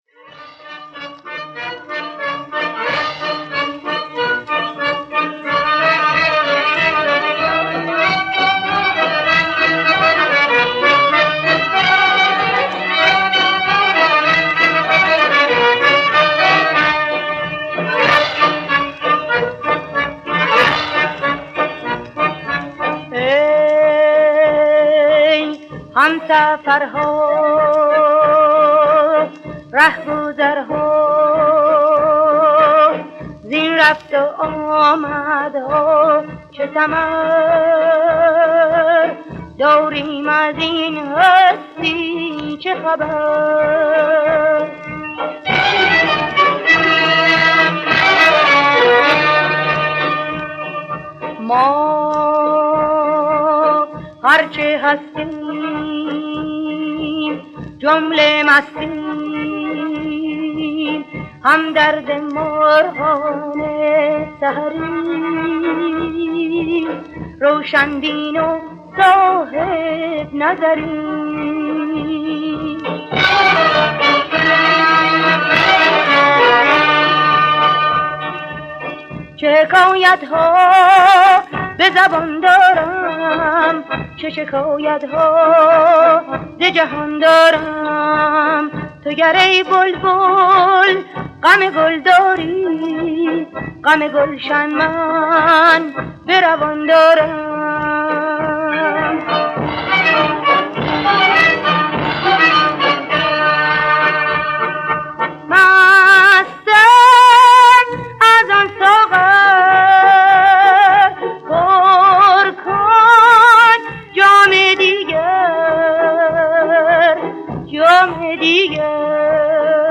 دستگاه: چهارگاه